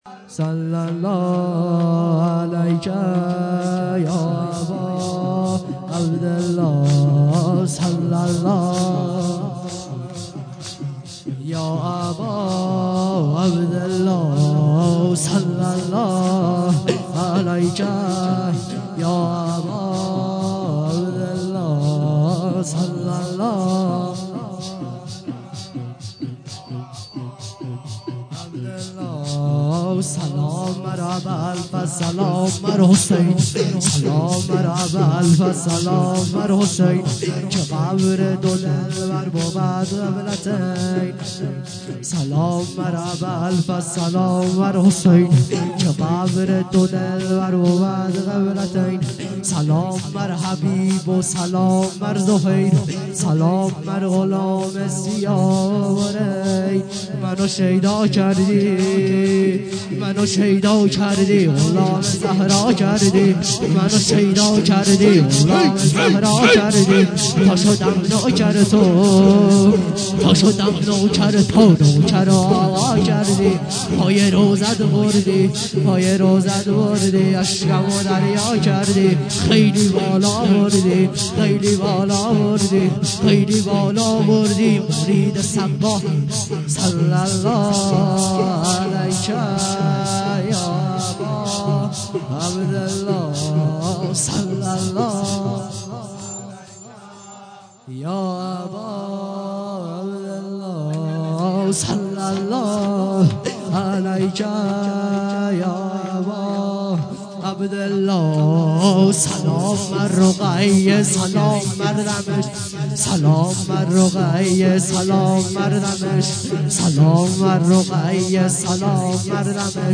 شب دوم محرم 96